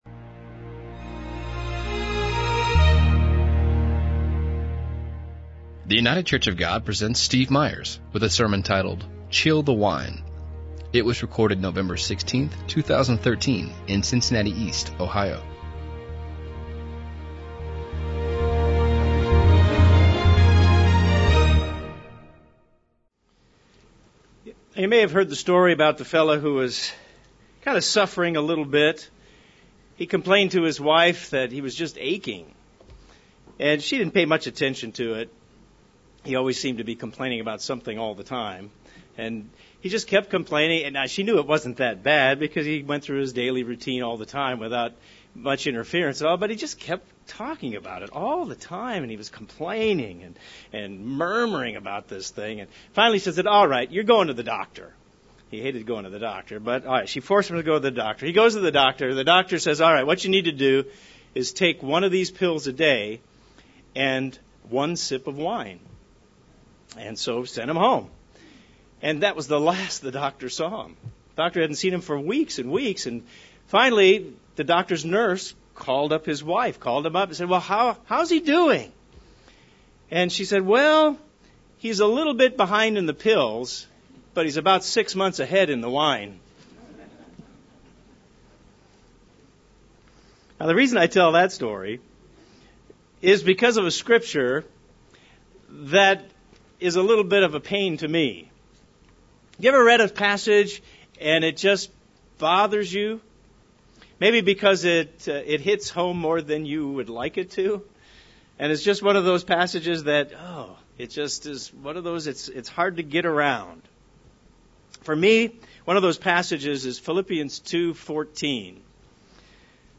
The Bible has a lot to say about whining. This sermon focuses on asking God to help us to see ourselves as we are so that we can think His thoughts.